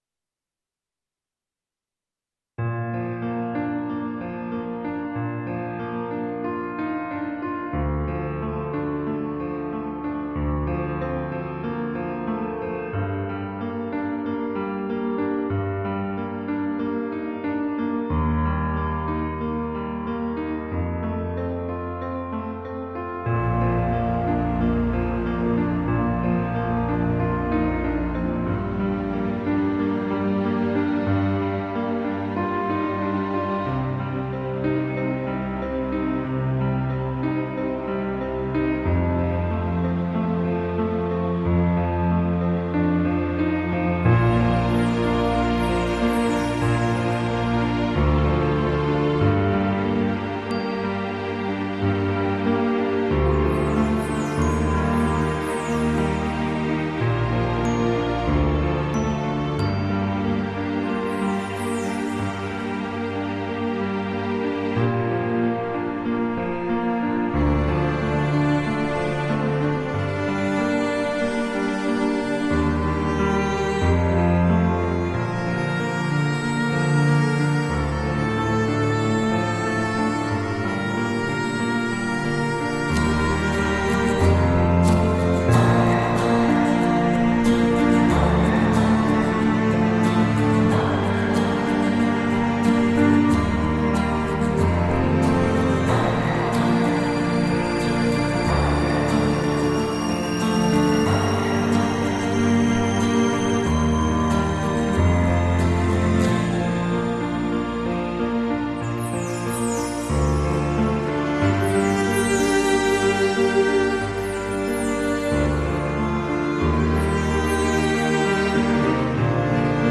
аранж. инструментал
Для начала хотел показать аранжировку без вокала...